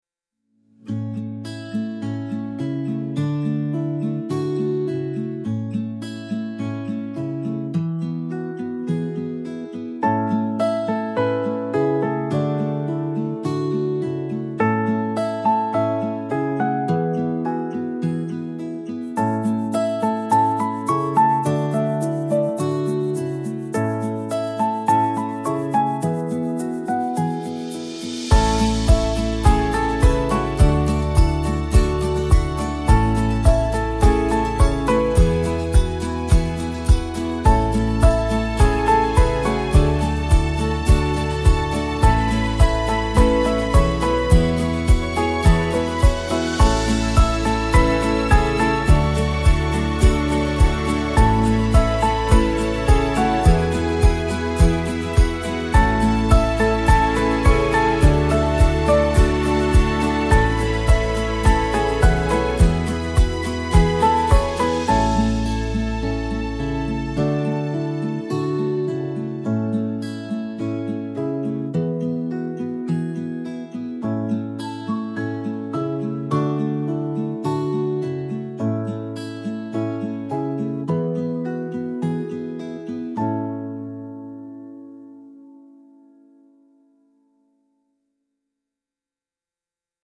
joyful remembrance music celebrating a beautiful life with bright acoustic tones